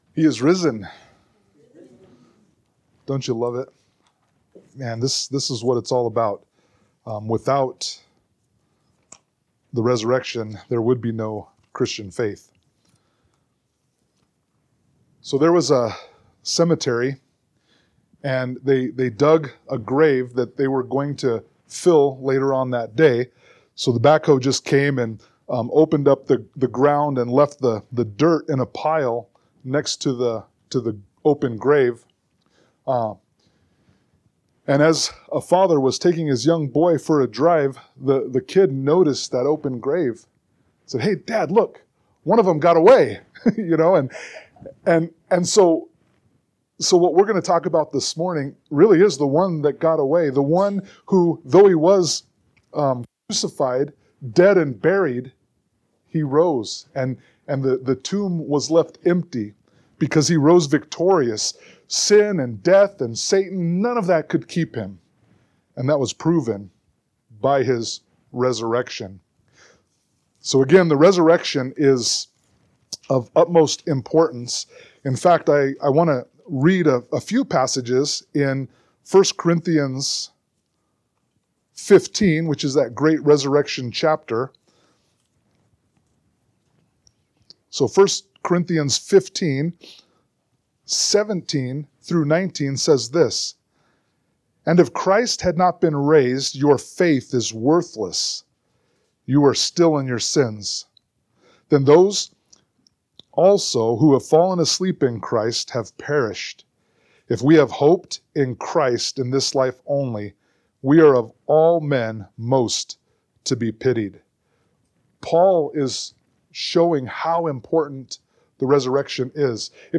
Sermon-4_20_25.mp3